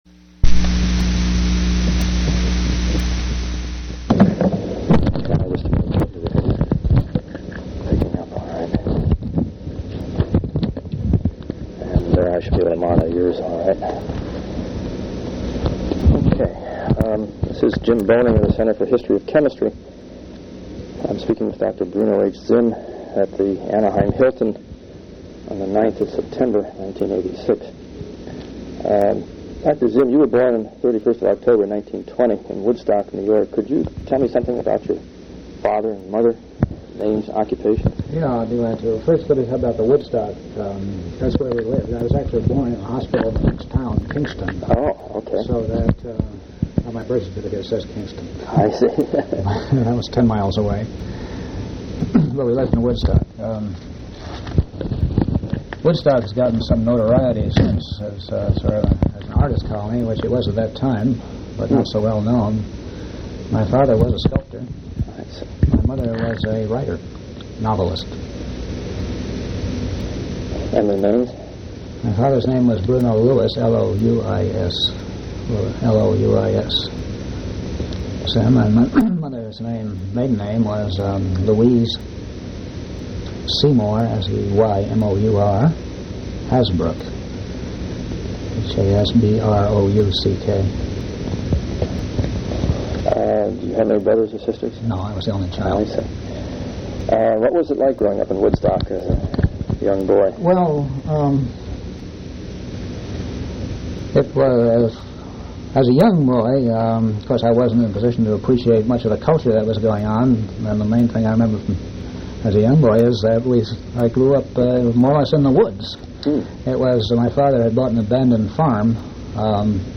Oral history interview with Bruno H. Zimm